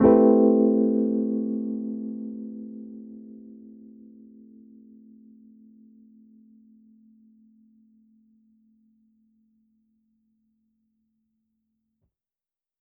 Index of /musicradar/jazz-keys-samples/Chord Hits/Electric Piano 3
JK_ElPiano3_Chord-Am9.wav